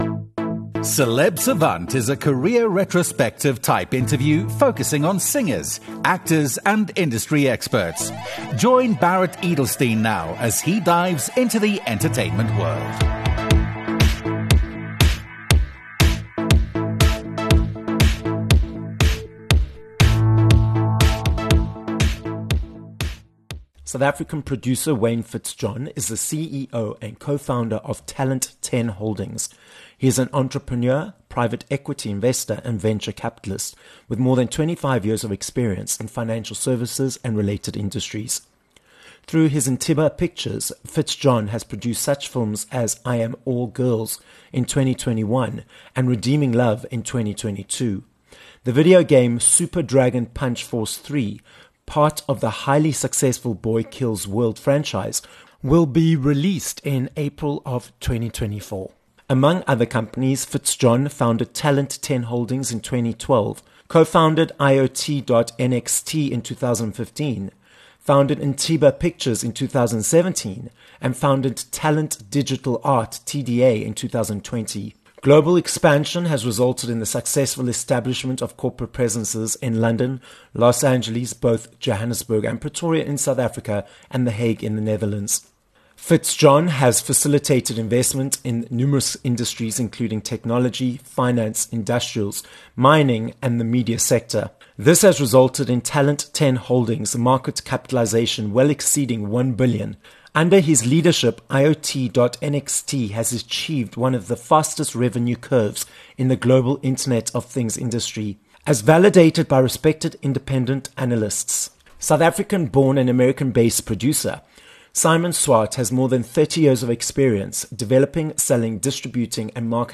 5 May Interview: Boy Kills World